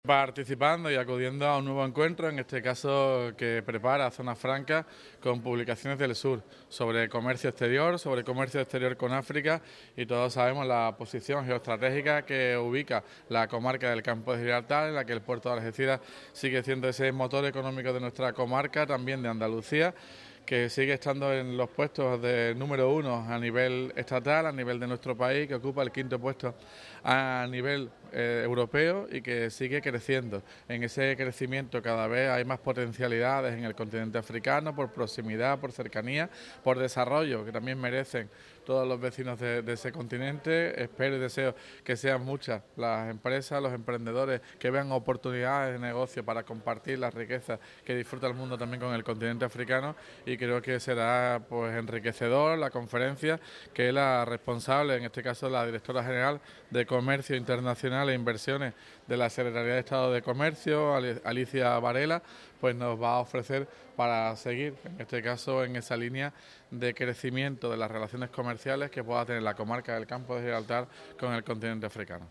JORNADAS_AFRICANAS_TOTAL_ALCALDE.mp3